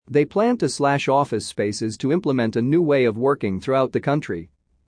【ノーマル・スピード】
答案の分析が済んだら、ネイティブ音声を完全にコピーするつもりで音読を反復してくださいね。